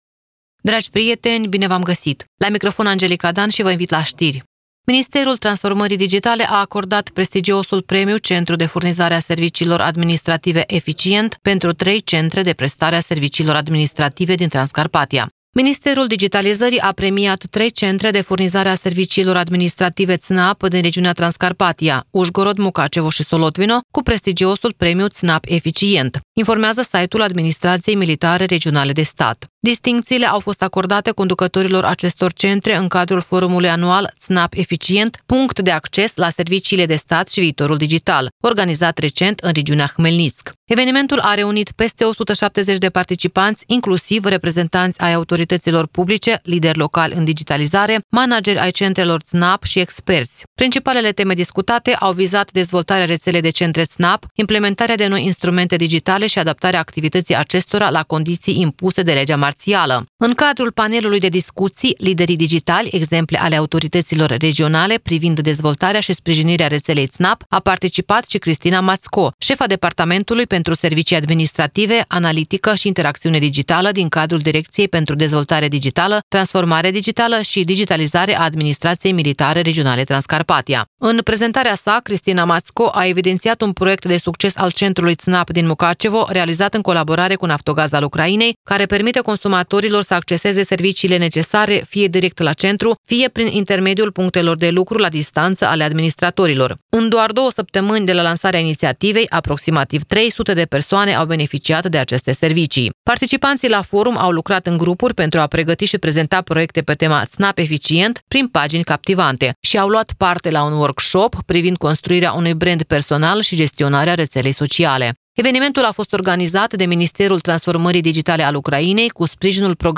Ştiri Radio Ujgorod – 10.12.2024